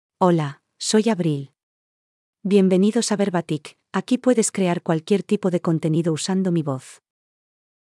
AbrilFemale Spanish AI voice
Abril is a female AI voice for Spanish (Spain).
Voice sample
Listen to Abril's female Spanish voice.
Abril delivers clear pronunciation with authentic Spain Spanish intonation, making your content sound professionally produced.